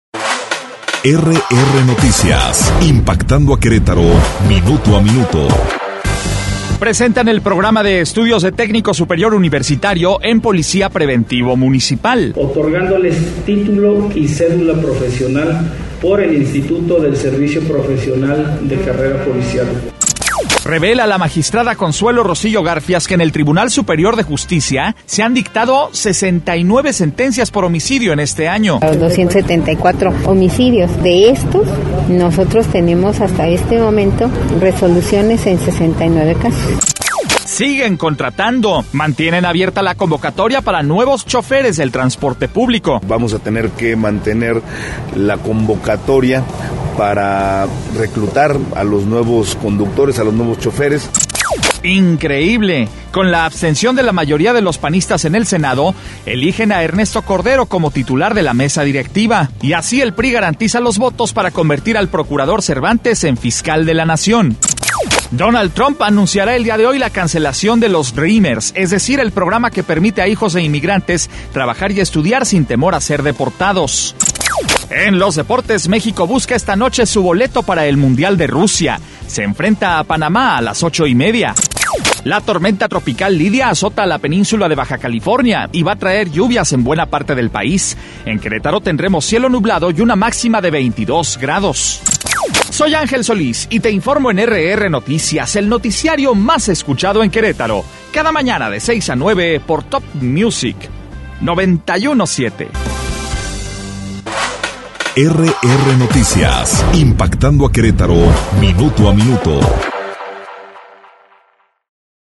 Resumen Informativo 1 de septiembre - RR Noticias